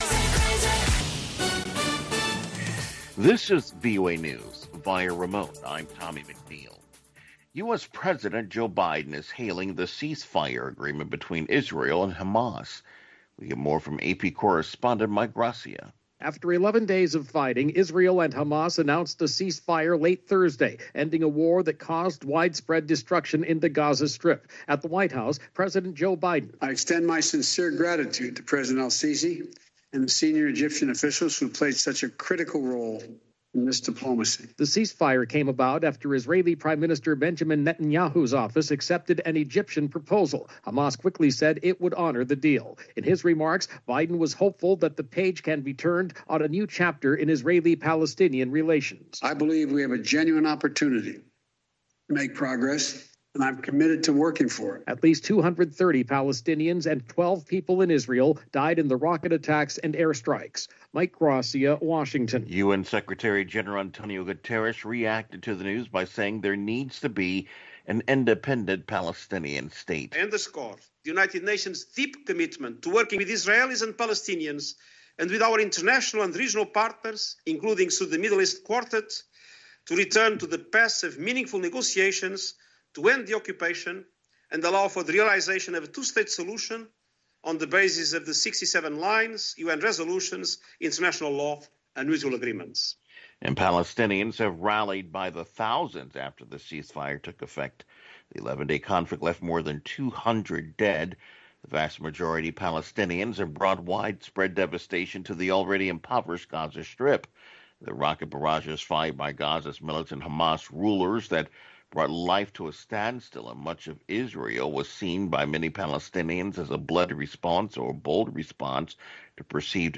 contemporary African music and conversation